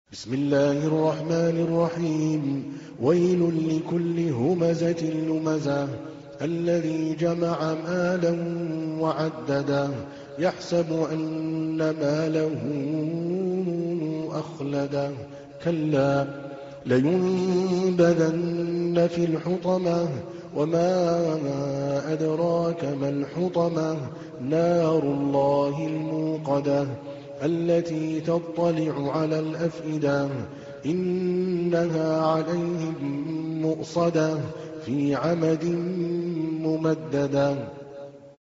تحميل : 104. سورة الهمزة / القارئ عادل الكلباني / القرآن الكريم / موقع يا حسين